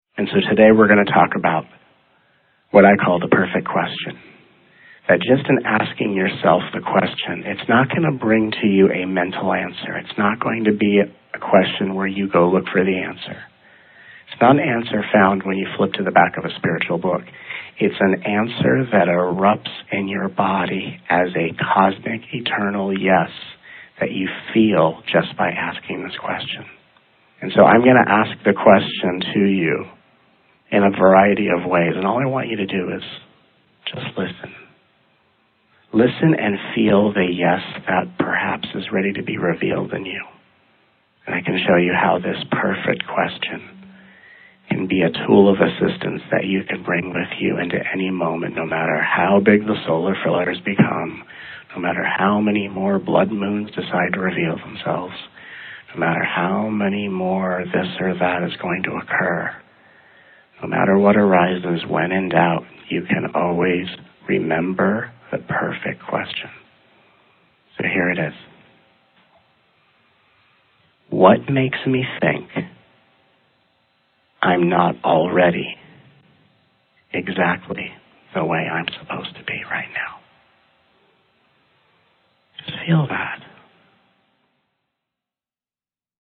A Course in Mastery: 7-week Telegathering Series to Awaken the Light of the Soul and Manifest your Highest Potential.
Total running time: 10½ hours of teachings, healing energies, activations, and exercises.